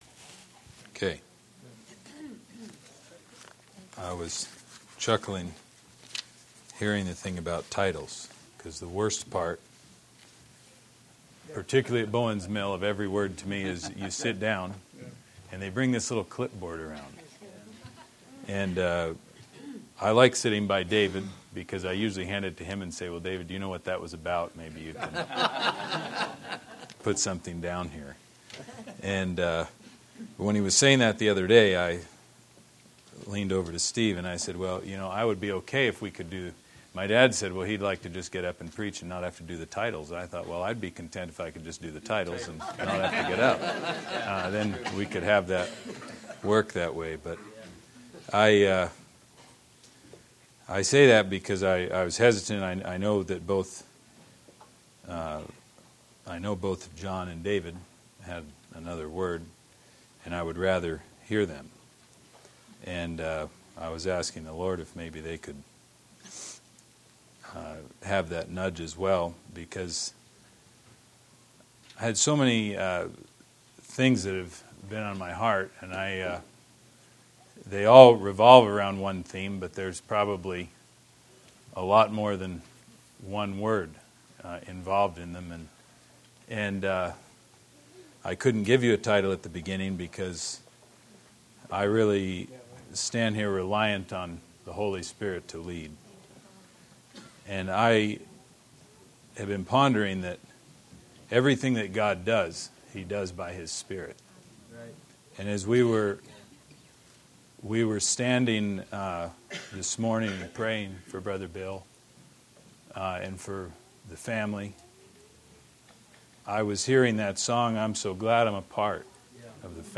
Shepherds Christian Centre Convention